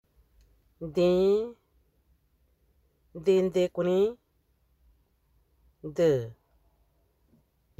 Accueil > Prononciation > d > d